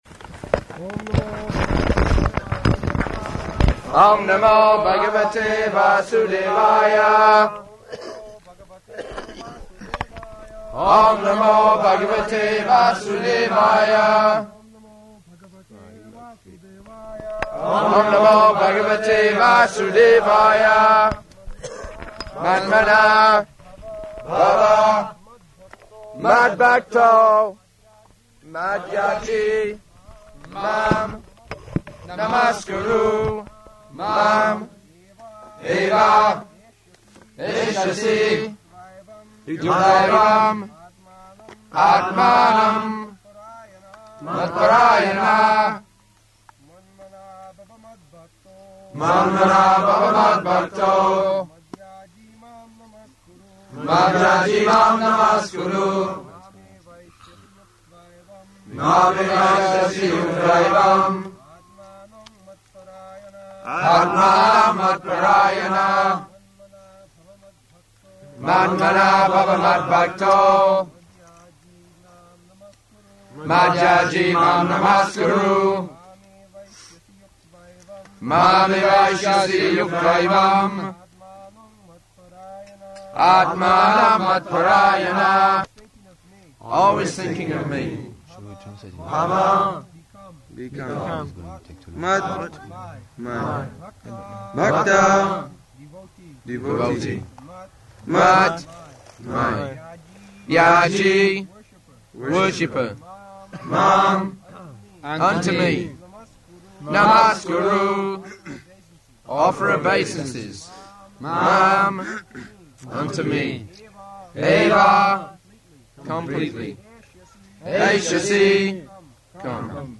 Location: New Māyāpur
[lecture takes place in the garden in the afternoon, under a tree]